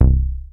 HOUSE MOOG 1 1.wav